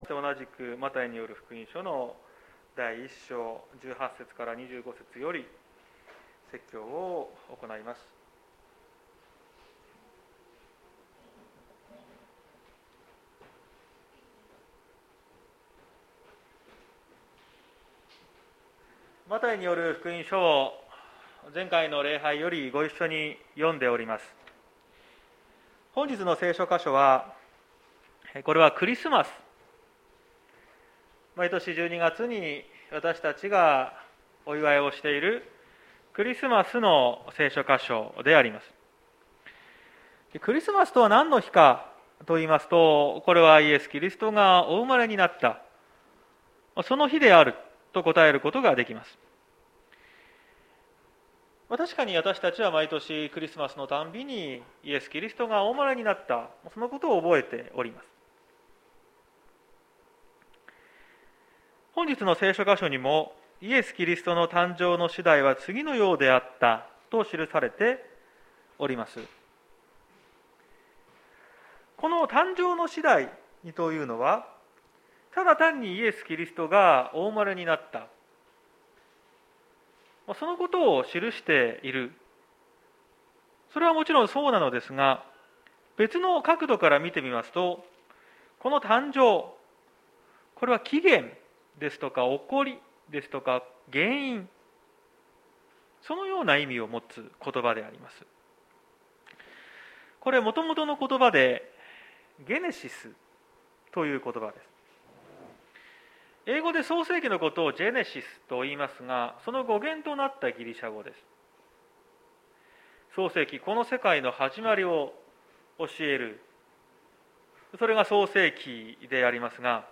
2022年02月20日朝の礼拝「インマヌエル」綱島教会
説教アーカイブ。